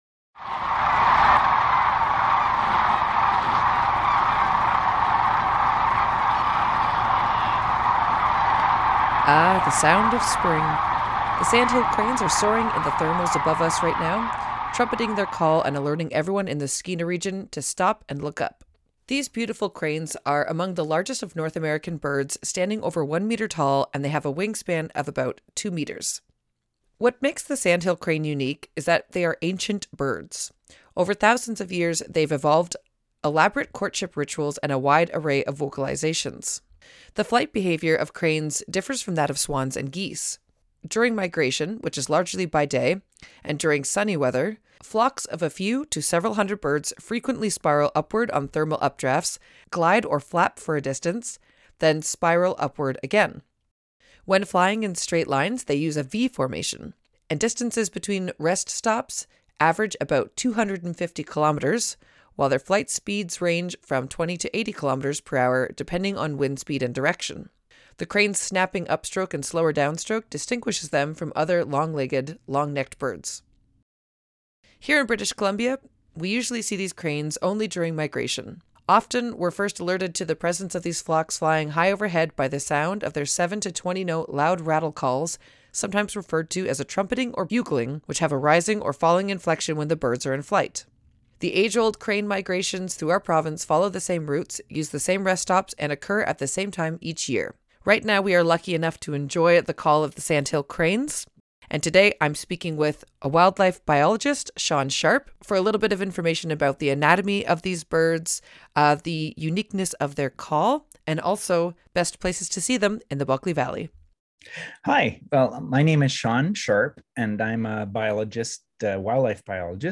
The sound of thousands of cranes trumpeting across the sky on sunny days in the Skeena Region of Northern BC marks the unofficial beginning of spring.